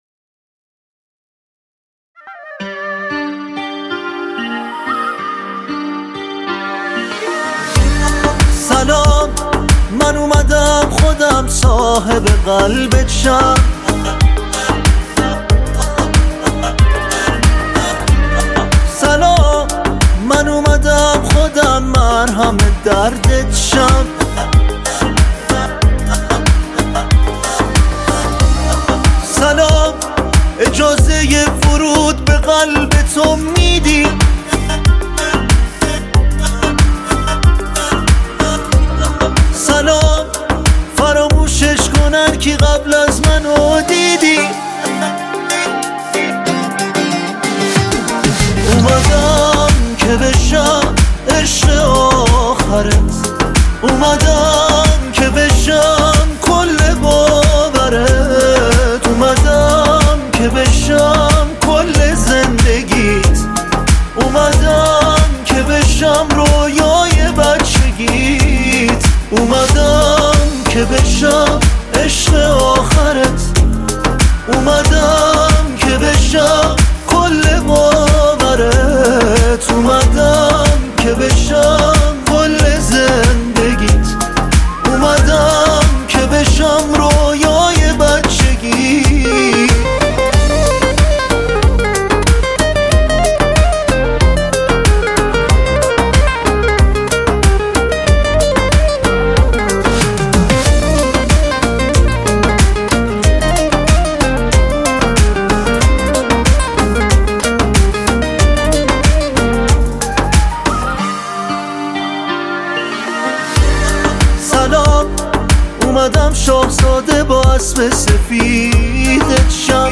آهنگ جدید و غمگین